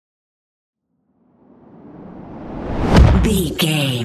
Dramatic whoosh to hit trailer
Sound Effects
Atonal
dark
intense
tension